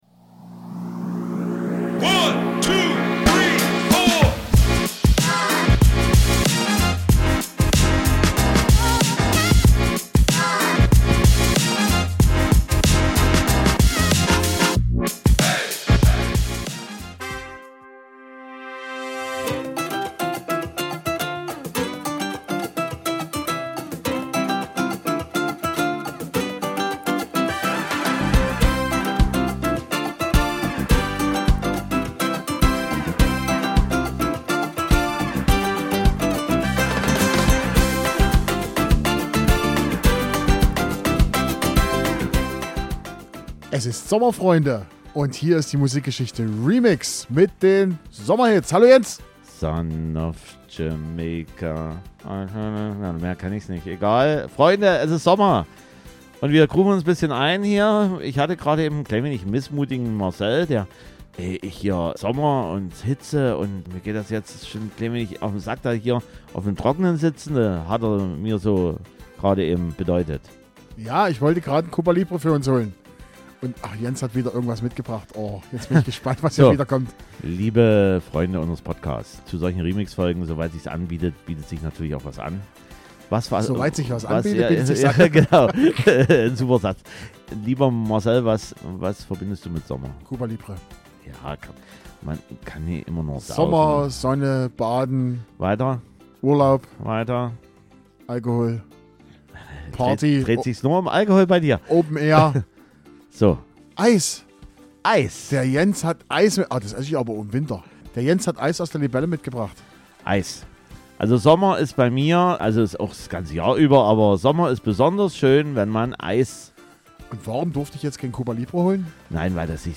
Zugegeben ist diese Remix-Folge und unsere Sommerhits etwas reggaelastig, aber wir wollten halt auch das gewisse Sommerfeeling über unsere Musikauswahl transportieren.